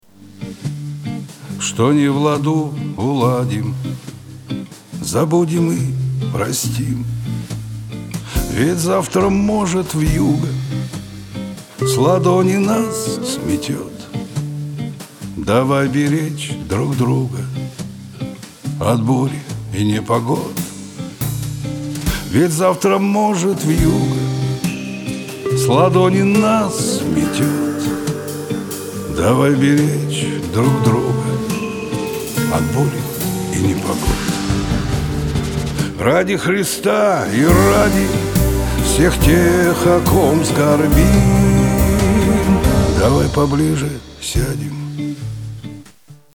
русский шансон
блатная песня
кабацкая песня